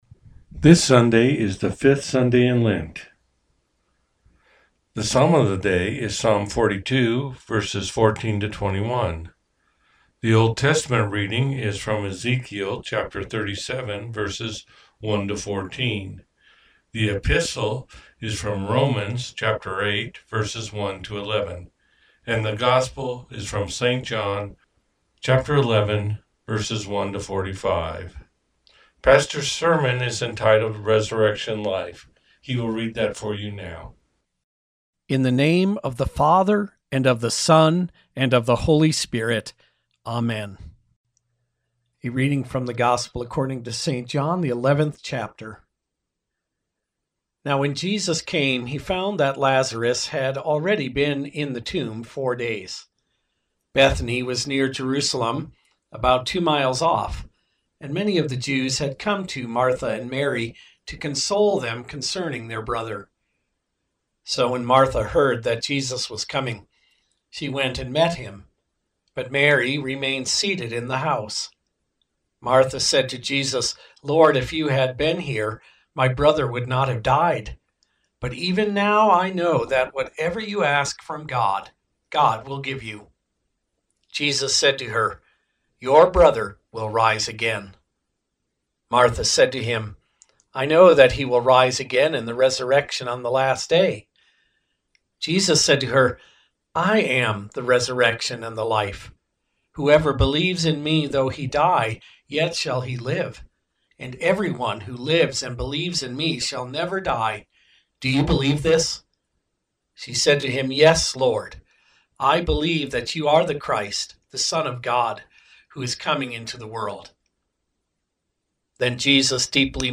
Lenten sermon preached at Peace With Christ Lutheran Church in Fort Collins.